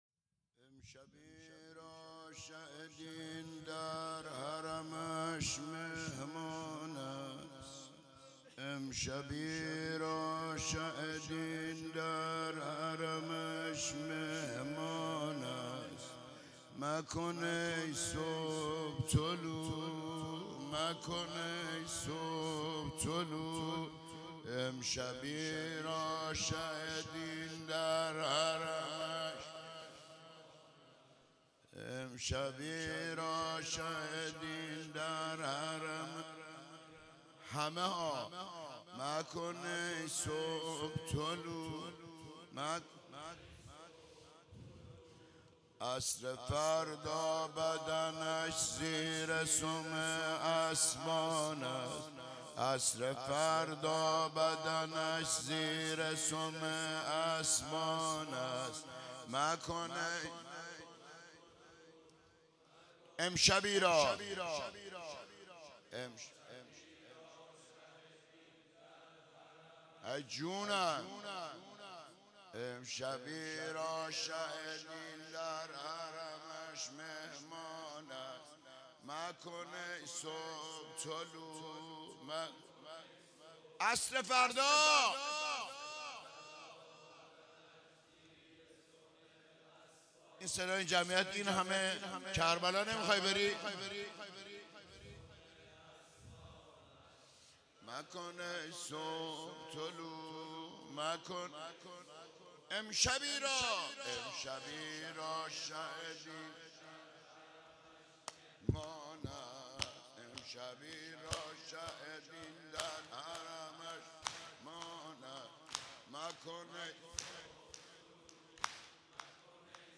روضه - امشبی را شه دین در حرمش مهمان است